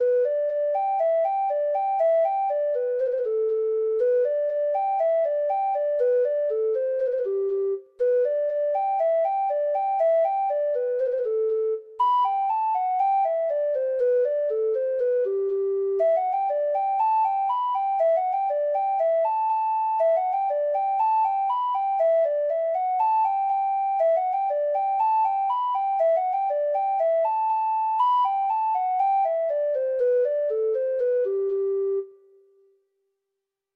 Traditional Trad. With A Mile Of Clonbur (Irish Folk Song) (Ireland) Treble Clef Instrument version
Folk Songs from O' Neills Music of Ireland Letter W With A Mile Of Clonbur (Irish Folk Song) (Ireland)
Irish